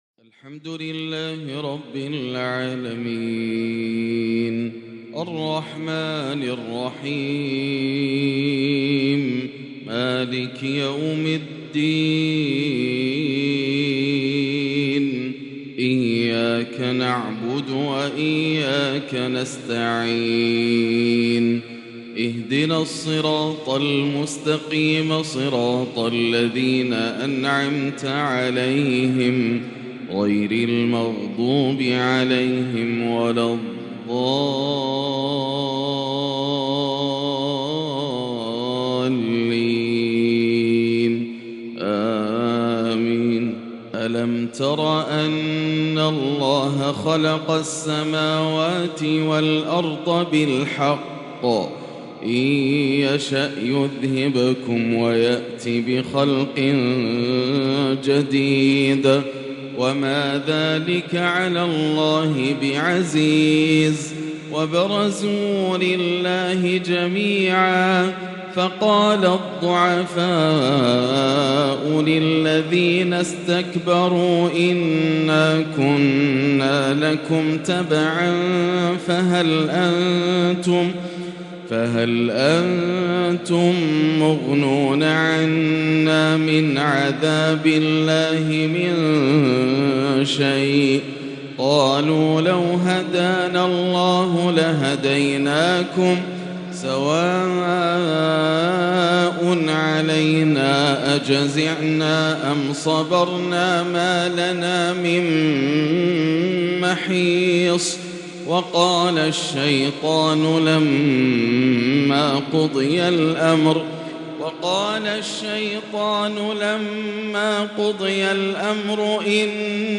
“أمن يجيب المضطر" آيات قرآنية عظيمة يحبرها د. ياسر الدوسري بالعجم الماتع والترتيل النجدي > مقتطفات من روائع التلاوات > مزامير الفرقان > المزيد - تلاوات الحرمين